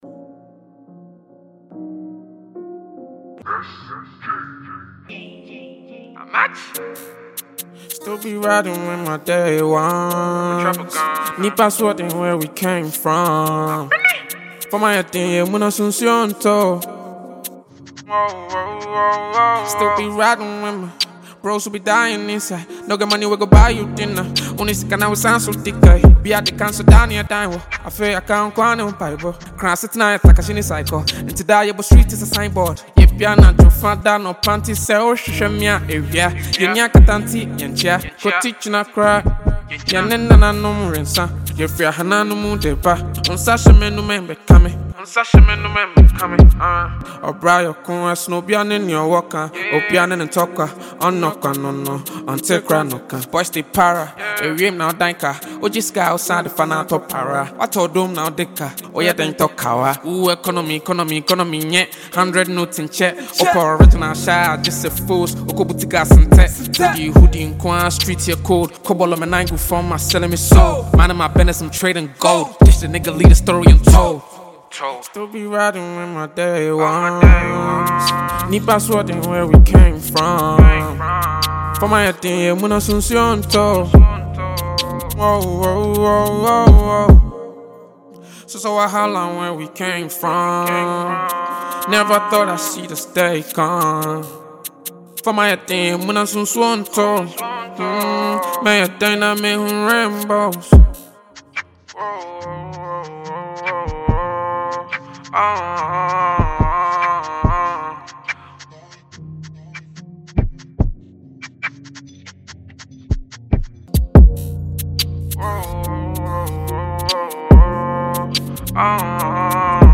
Ghanaian rapper
freestyle